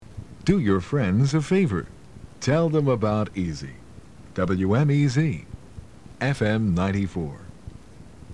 Jingle #1 (mid 1980's)